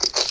damage.ogg